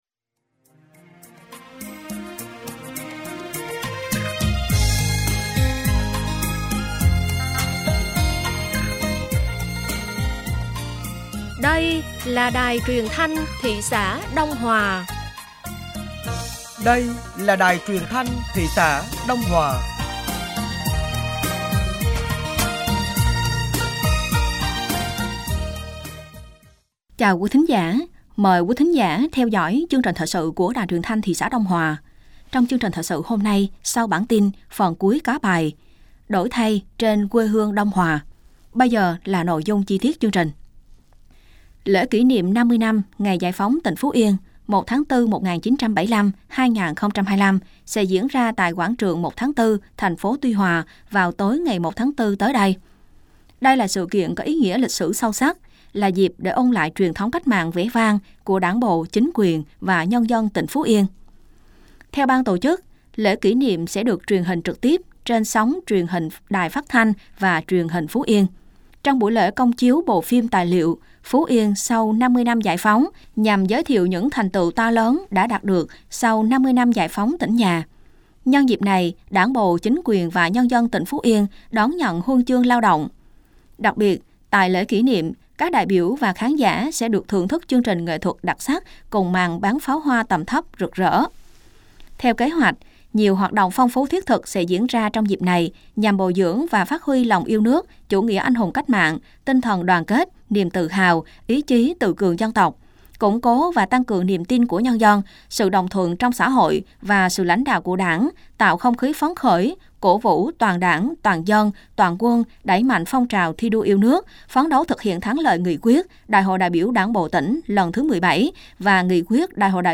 Thời sự tối ngày 19 và sáng ngày 20 tháng 3 năm 2025